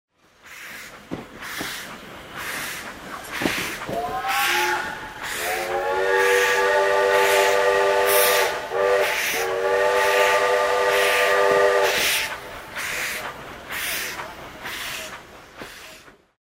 Звуки гудков паровоза
Пшики паровоза и гудки